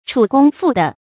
楚弓复得 chǔ gōng fù dé
楚弓复得发音